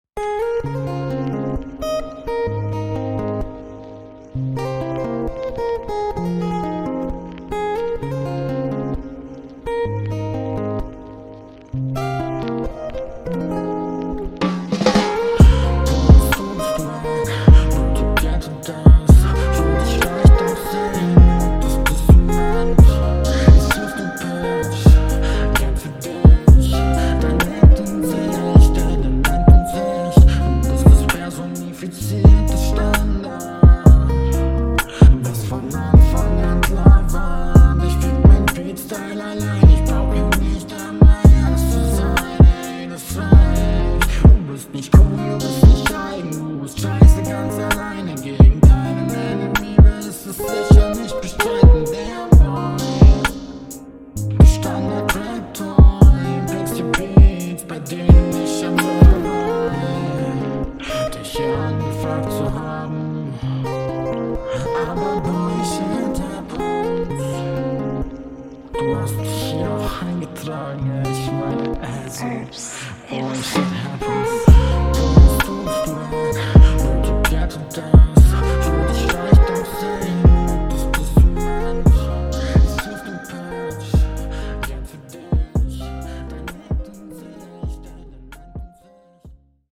Flow: Du klingst ganz cool.